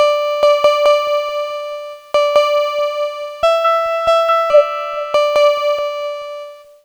Cheese Lix Synth 140-D.wav